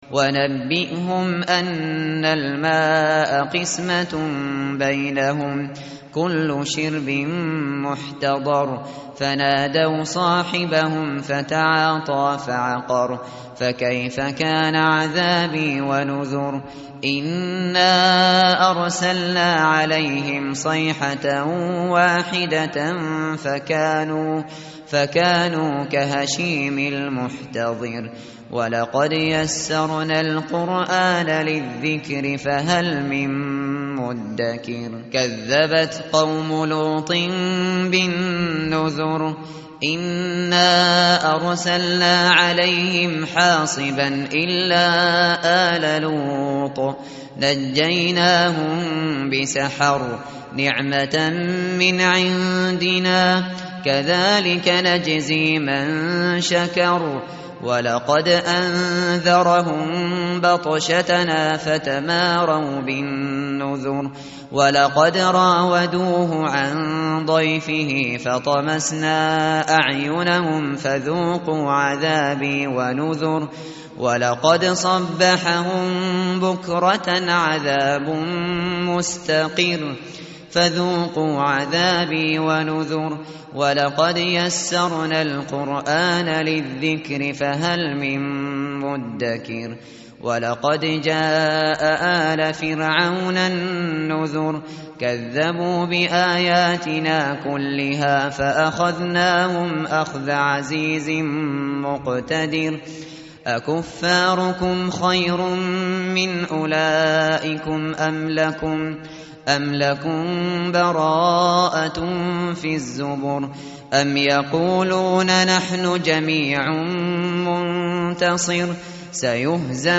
متن قرآن همراه باتلاوت قرآن و ترجمه
tartil_shateri_page_530.mp3